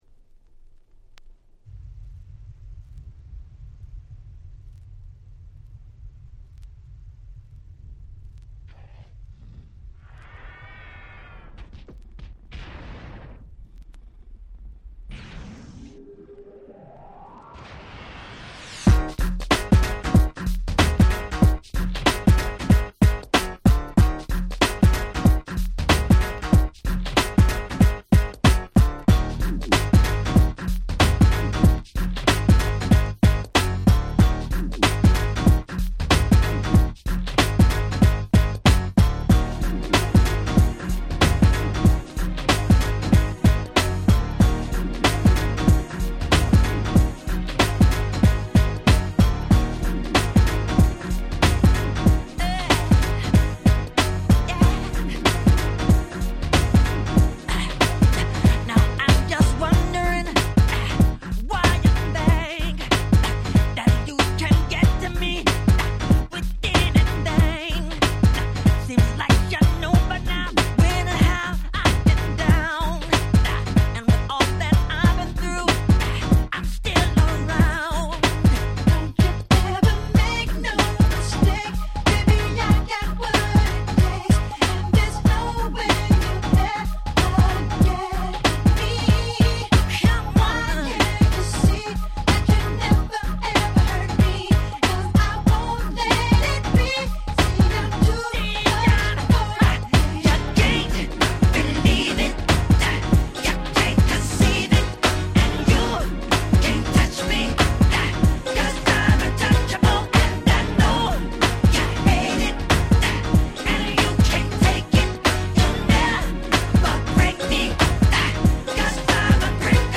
01' Nice R&B !!